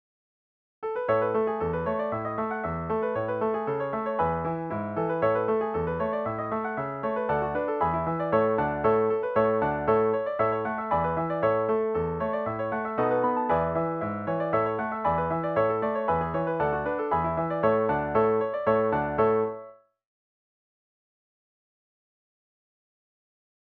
DIGITAL SHEET MUSIC - PIANO ACCORDION SOLO
Traditional Tunes, Scottish Hornpipe